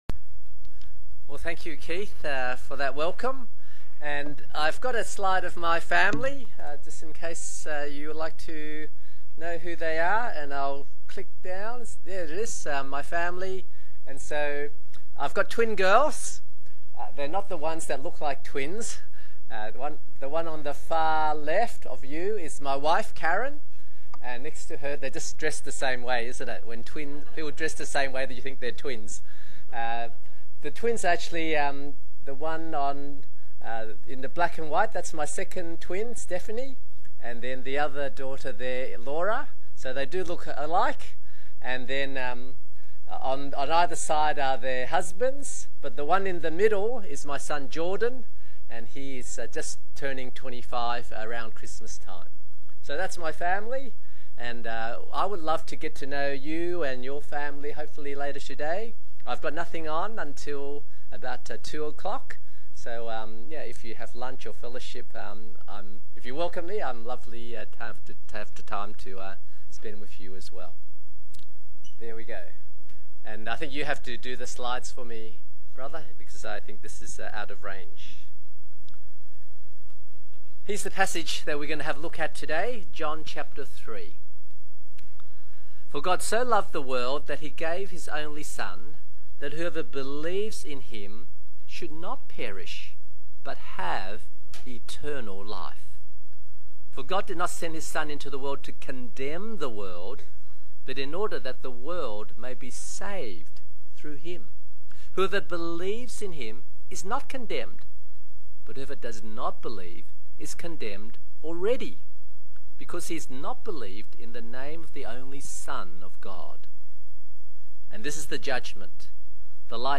English Worship (LCK) - Getting Ready for Christmas